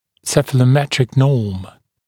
[ˌsefələˈmetrɪk nɔːm][ˌсэфэлэˈмэтрик но:м]цефалометрическая норма